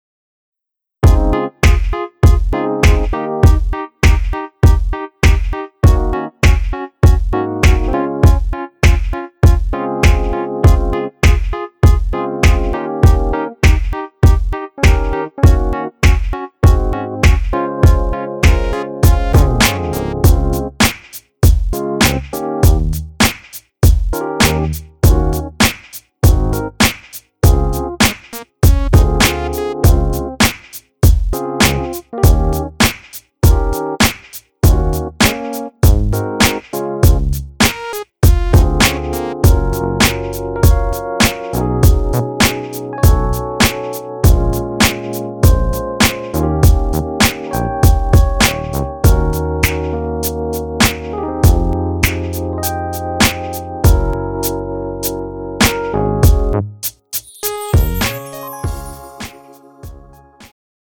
장르 가요 구분 Pro MR